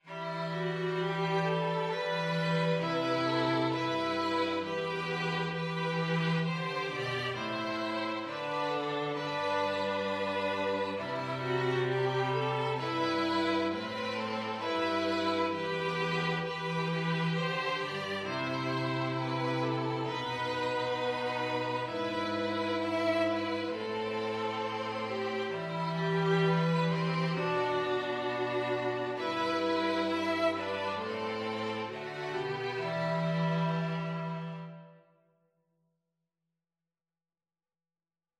Violin 1Violin 2Cello
4/4 (View more 4/4 Music)